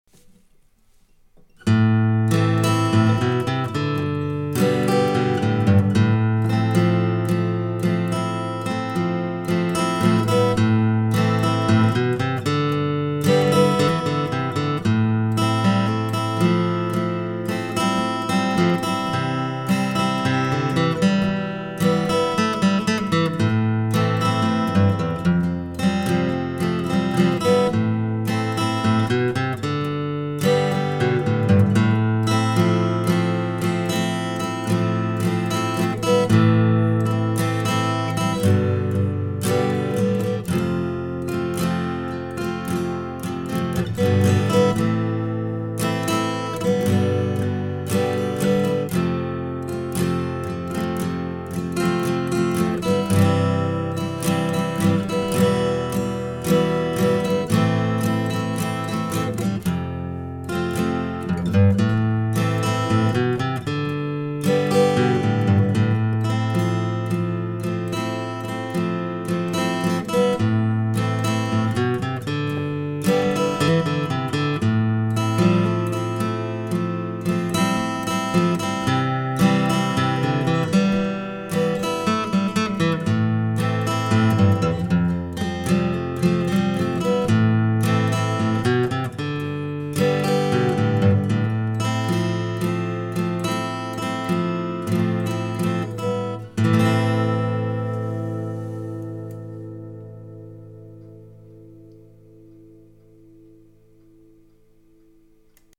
cette jolie ballade est plutôt facile à apprendre.